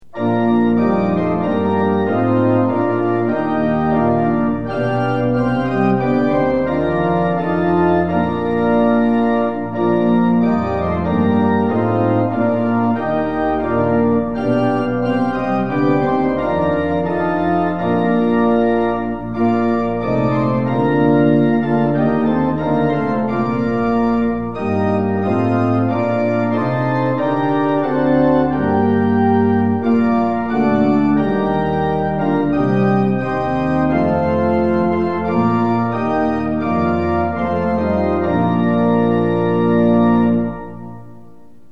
organ rendition of the tune